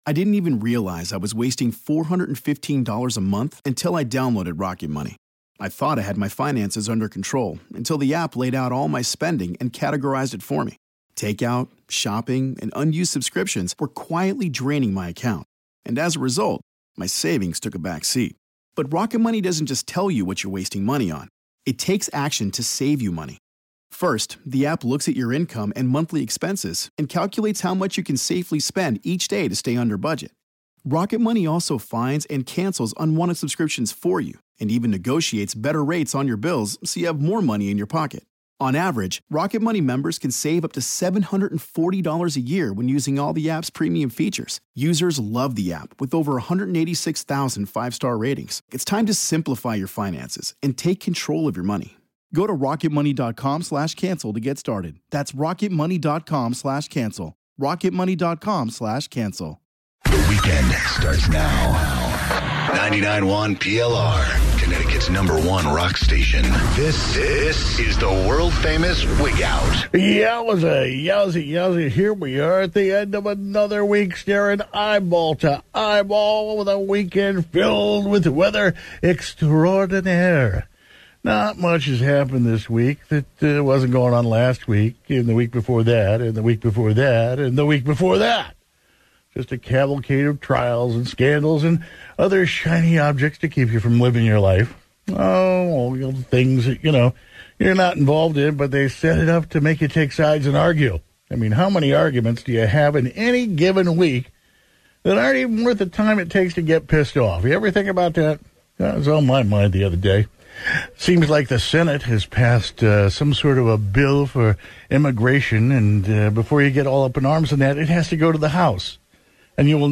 Live Wigout